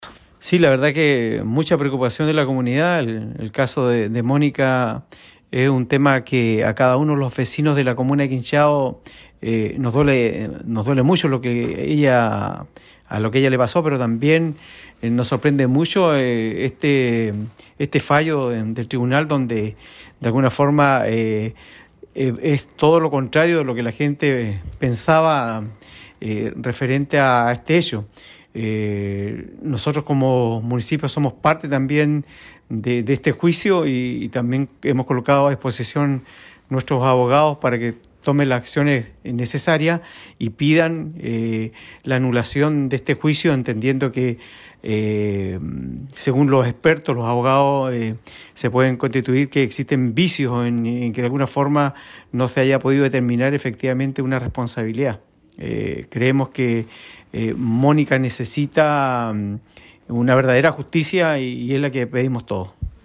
Insistió en que el equipo jurídico prestará todo el apoyo a la familia para que puedan requerir a las instancias que corresponda, la nulidad de este juicio y que nuevamente se lleve a juicio a quien es sindicado como el culpable del femicidio, dijo el alcalde René Garcés.